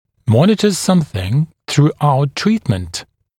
[‘mɔnɪtə ‘sʌmθɪŋ θru’aut ‘triːtmənt][‘монитэ ‘самсин сру’аут ‘три:тмэнт]наблюдать за ч.-л. в течение всего лечения, контролировать ч.-л. в течение всего периода лечения